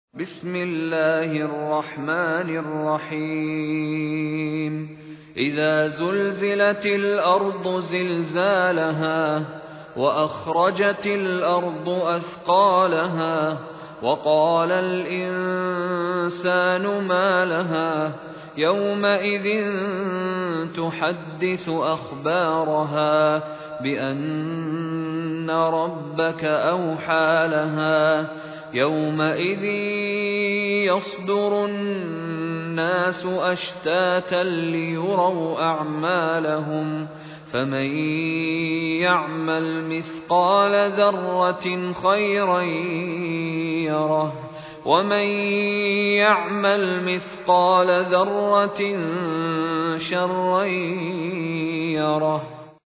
المصاحف - مشاري بن راشد العفاسي
المصحف المرتل - حفص عن عاصم